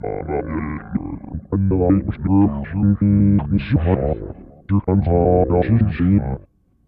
Звуки электронного голоса
Звук невнятного произношения слов электронным голосом компьютера